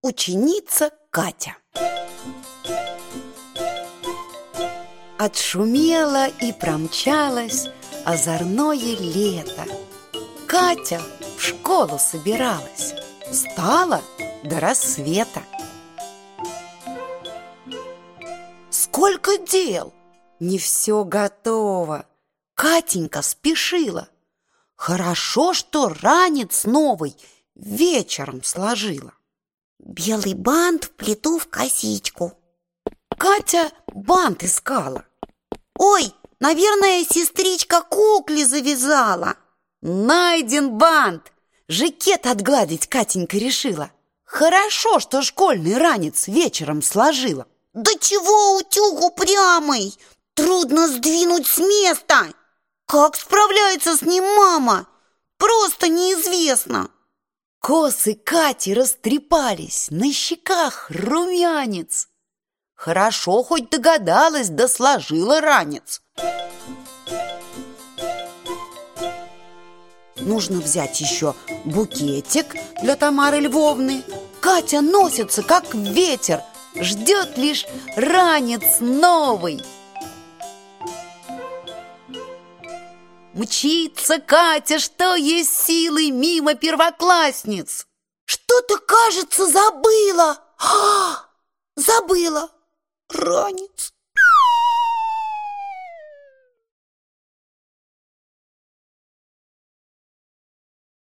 Аудиокнига Сказочное воспитание | Библиотека аудиокниг